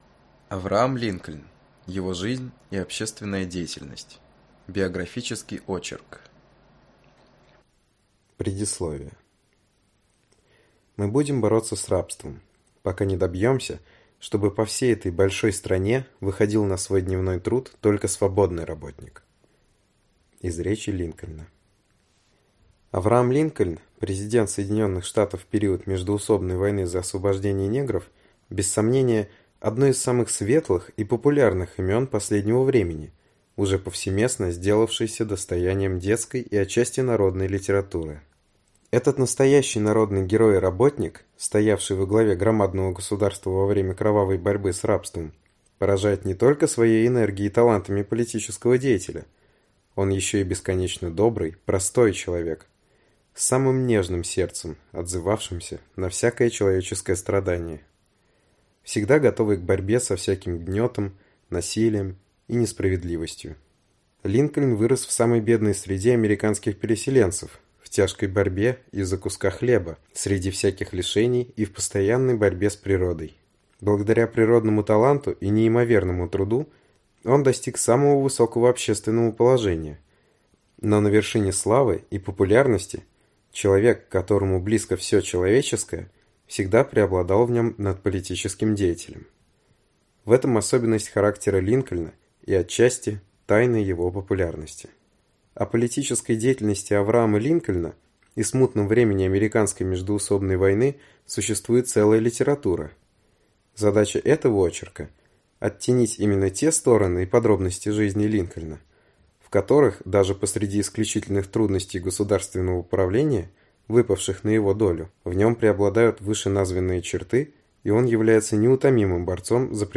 Аудиокнига Авраам Линкольн. Его жизнь и общественная деятельность | Библиотека аудиокниг